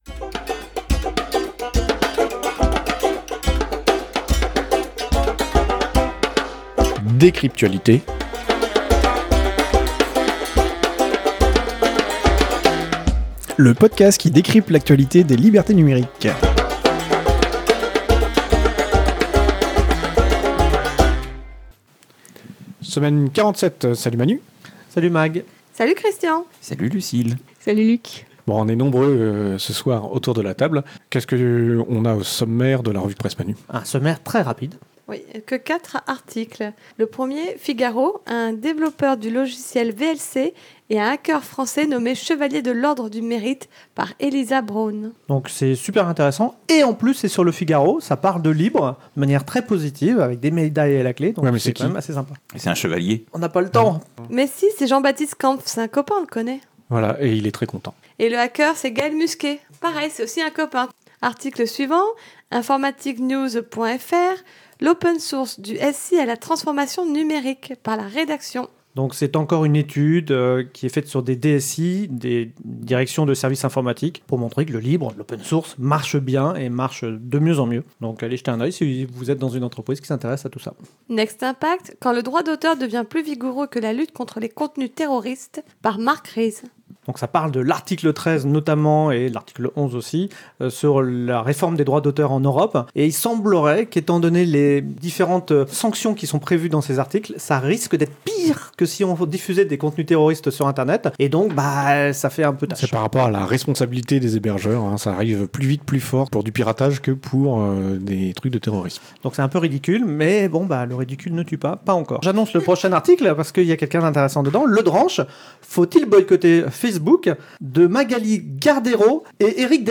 Lieu : April - Studio d'enregistrement